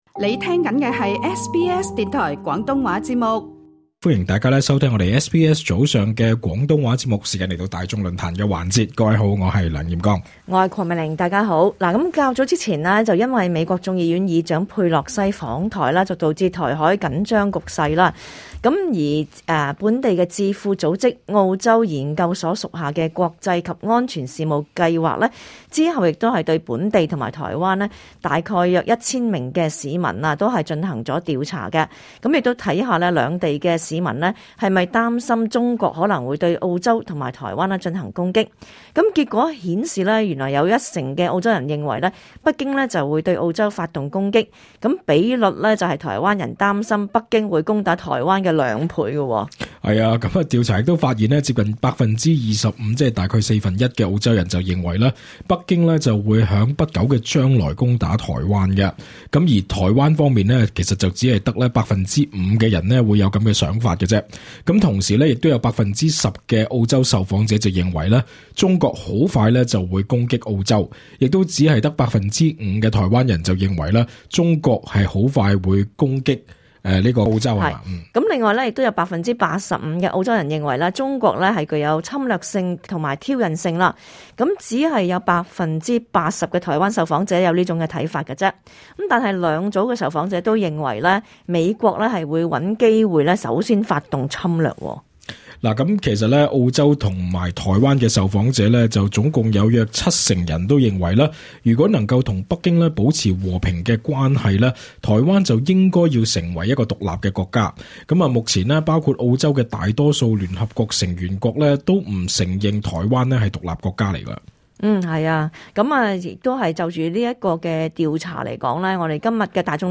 就此調查，在今集【大眾論壇】亦想問問大家：『你是否擔心中國會在不久將來對澳洲進行攻擊？』 *本節目內嘉賓及聽眾意見並不代表本台立場 READ MORE 教育子女遠離暴力尊重他人 家長應以身作則 【為台海衝突準備】國防部委託研究報告：澳洲9成進口燃料受影響 中國疫情後大推基建 澳洲礦商將受益 瀏覽更多最新時事資訊，請登上 廣東話節目 Facebook 專頁 、 MeWe 專頁 、 Twitter 專頁 ，或訂閱 廣東話節目 Telegram 頻道 。